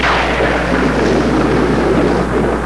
Thunder
thunder.wav